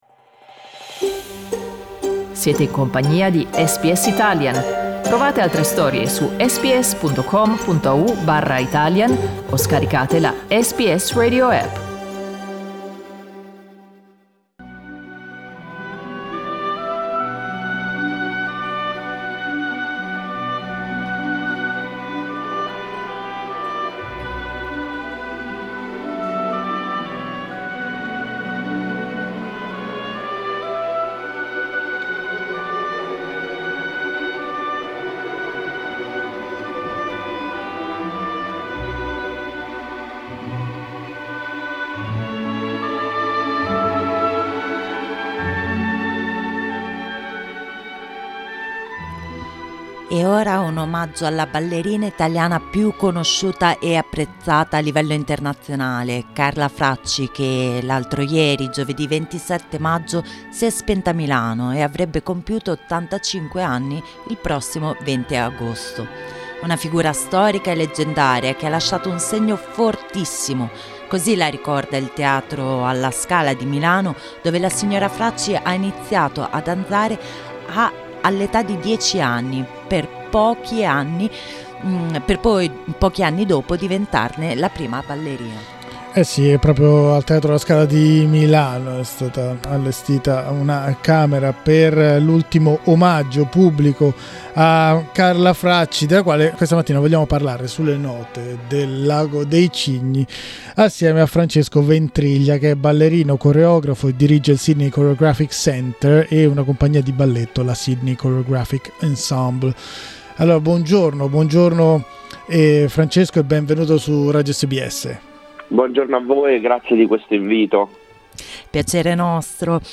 Il coreografo e ballerino